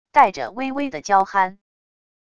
带着微微的娇憨wav音频生成系统WAV Audio Player